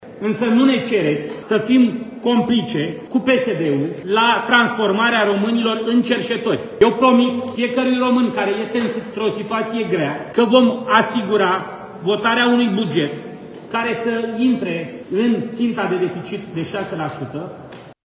Președintele AUR a dat de înțeles că nu va vota cot la cot cu PSD la adoptarea amendamentelor în plen: